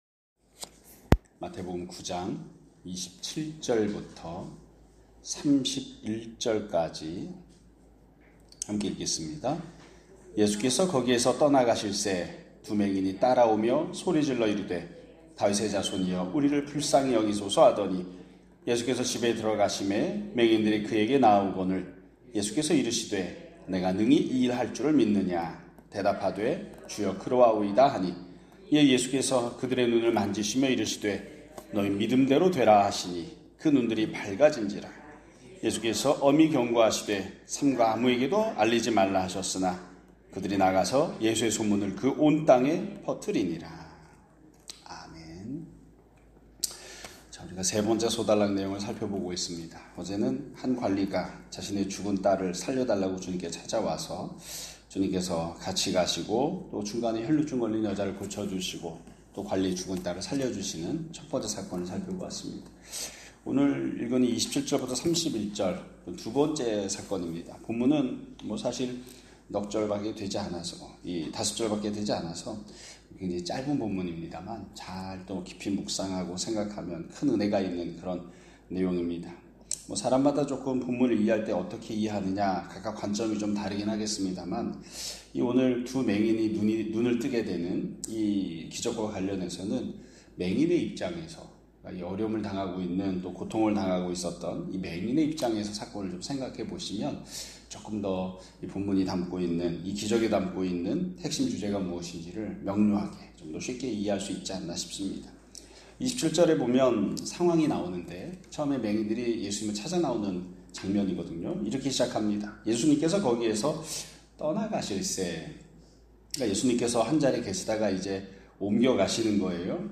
2025년 7월 24일 (목요일) <아침예배> 설교입니다.